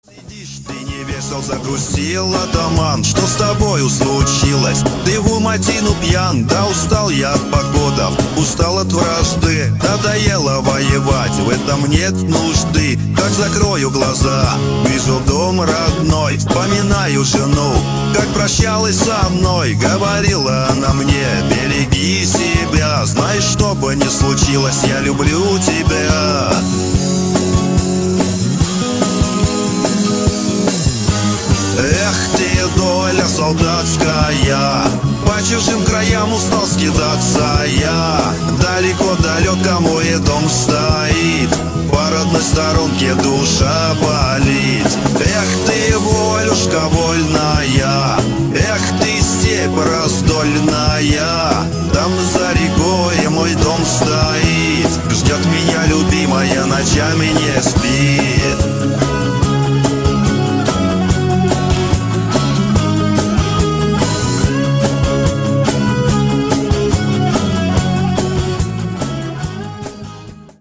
• Качество: 224, Stereo
Cover
Нейросеть